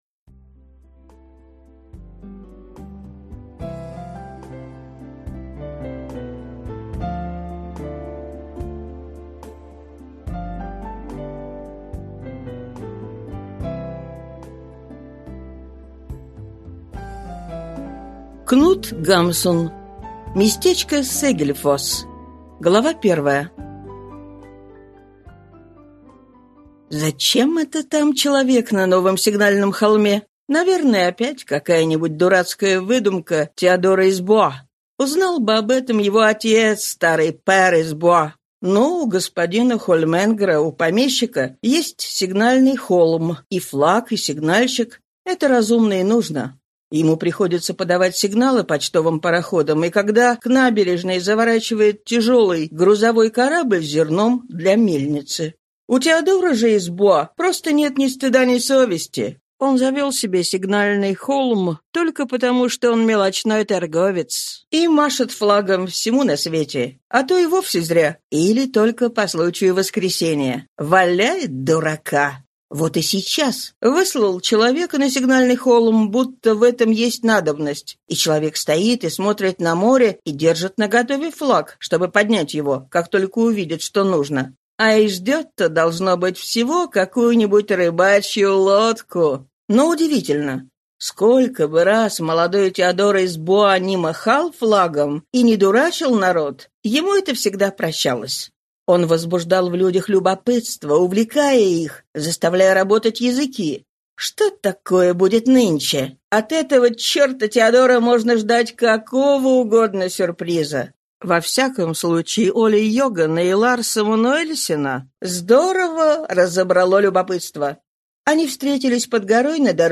Аудиокнига Местечко Сегельфосс | Библиотека аудиокниг